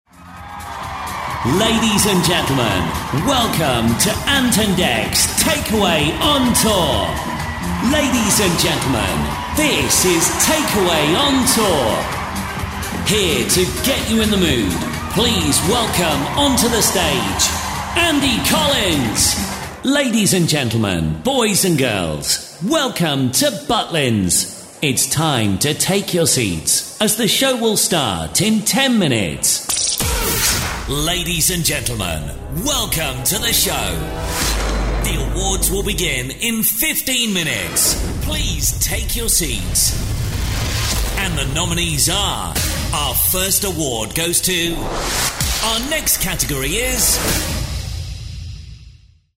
Powerful delivery, fast turnaround and pre-recorded options.
Voice of God Announcer & Voiceover
The Voice of God is the powerful, unmistakable live event announcer voice that guides audiences through major events, theatre shows, awards ceremonies and arena tours.